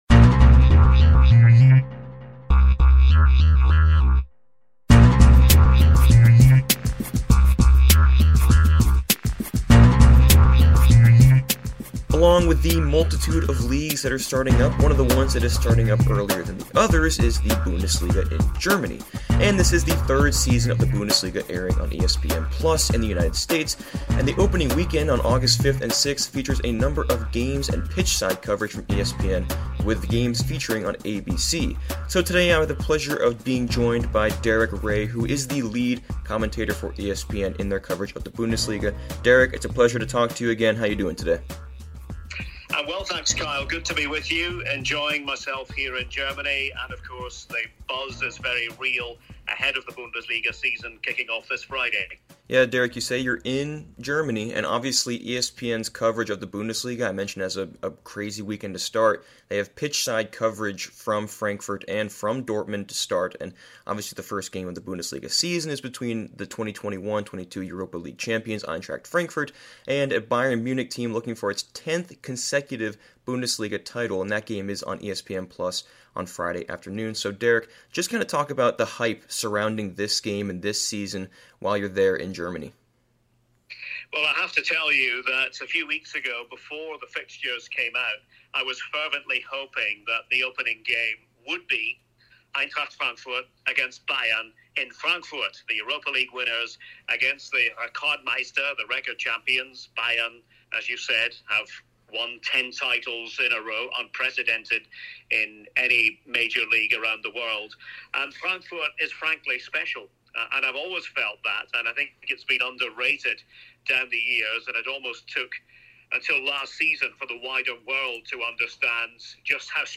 In this audio interview